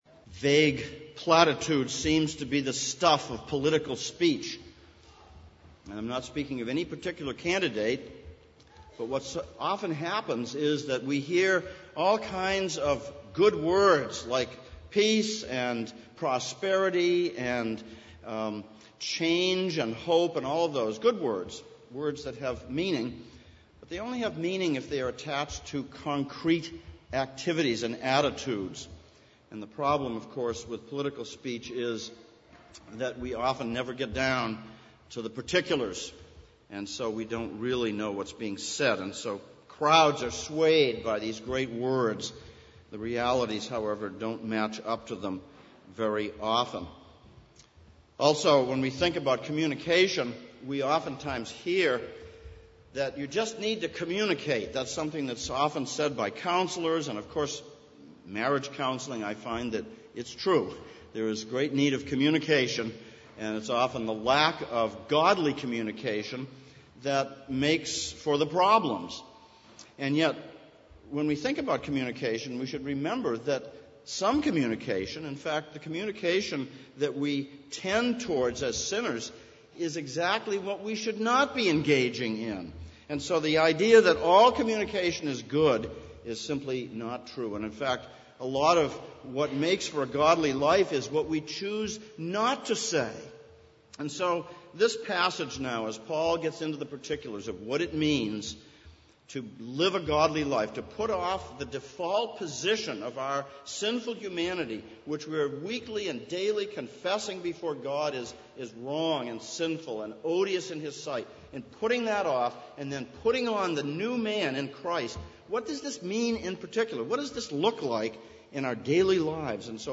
Sermon
Ephesians 4:17-32 Service Type: Sunday Morning Sermon